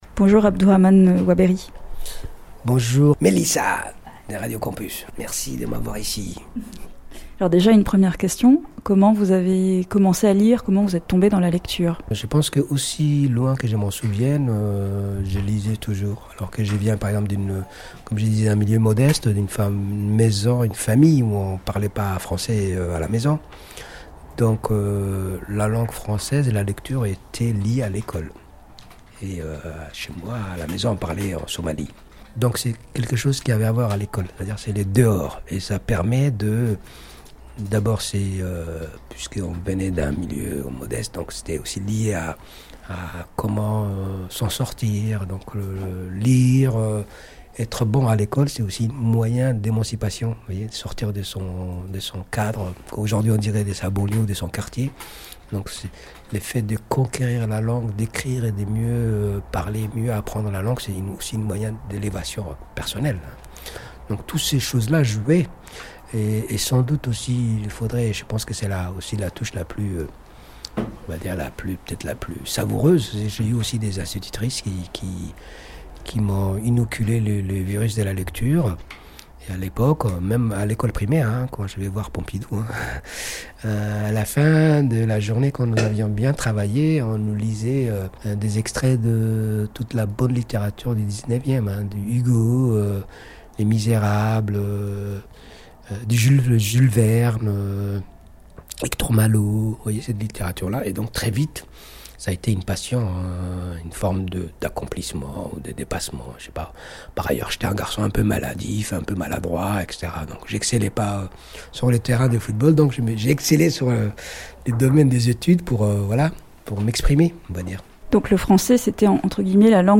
Auteur et professeur, voici Abdourahman Waberi. Merci à lui d’avoir accepté de répondre à quelques questions après sa conférence pour les étudiants de l’Université de Tours, dans le cadre du Festival Plumes d’Afrique.